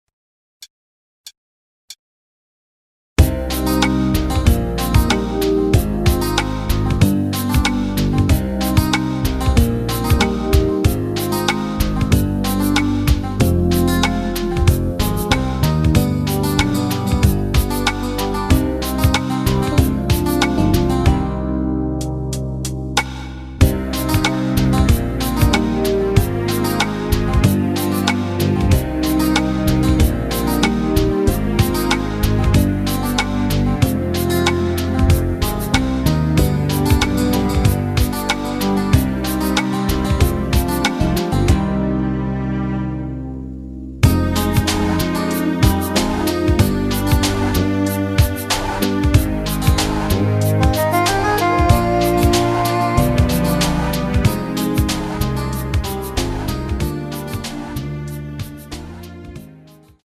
전주없이 시작하는 곡이라 전주 카운터 넣어 놓았습니다.
Cm
◈ 곡명 옆 (-1)은 반음 내림, (+1)은 반음 올림 입니다.
앞부분30초, 뒷부분30초씩 편집해서 올려 드리고 있습니다.